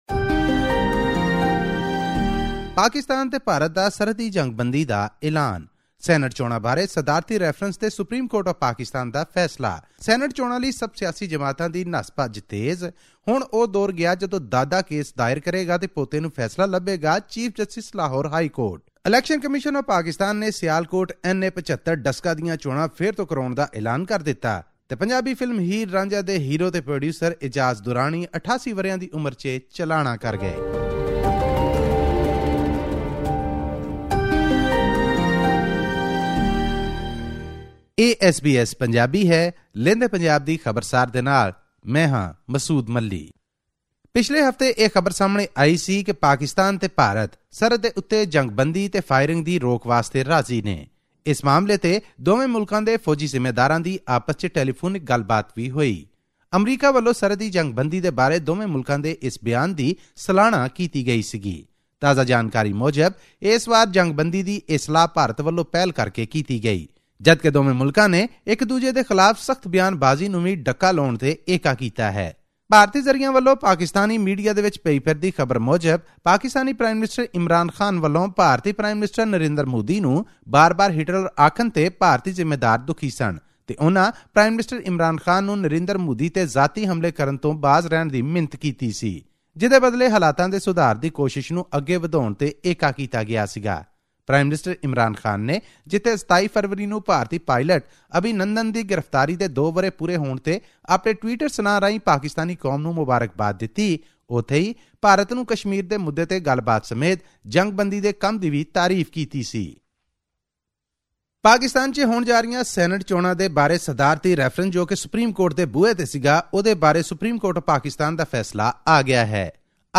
In our weekly news report from Pakistan’s Punjab province, a new truce at the LOC (Line of Control) is expected to avoid problems that have disrupted the past attempts of peace between the two neighbouring countries.